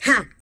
23 RSS-VOX.wav